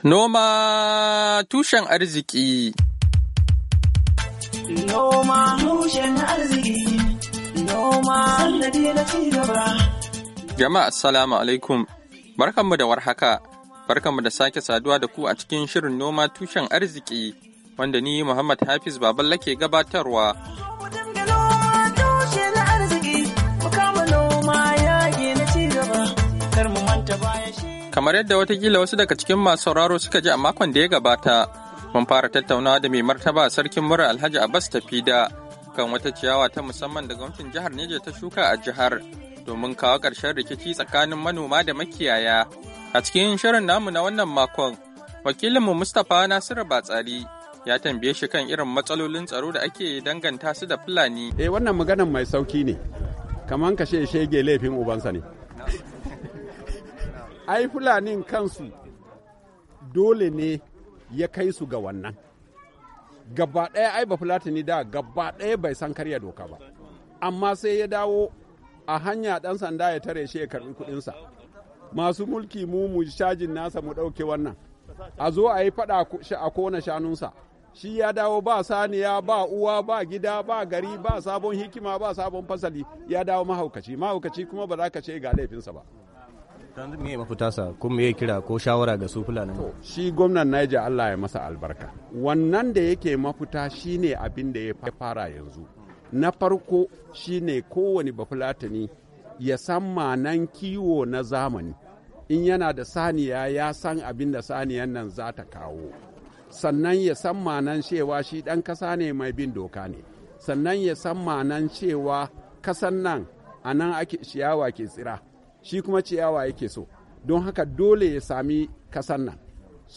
NOMA TUSHEN ARZIKI: Hirar Da Mai Martaba Sarkin Muri, Alhaji Abbas Tafida, Kan Manoma Da Makiyaya, Kashi Na Biyu - 18, Agusta, 2020